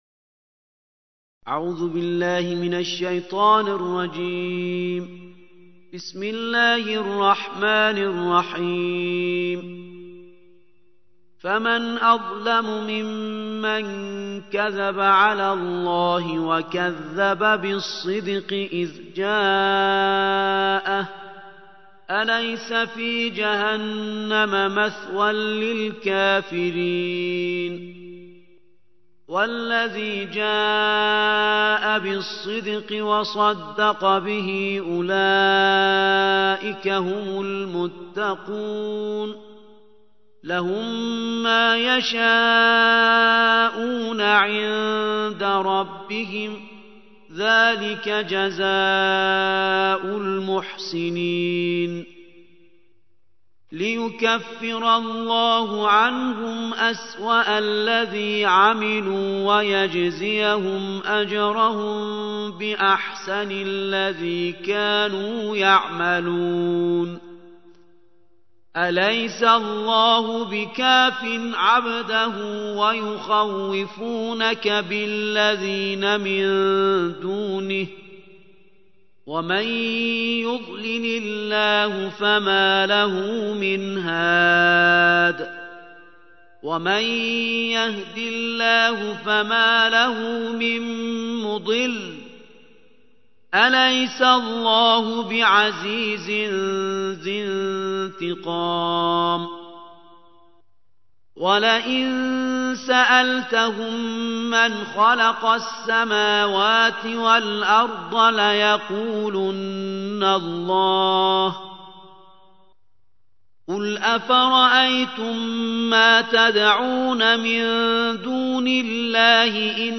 الجزء الرابع والعشرون / القارئ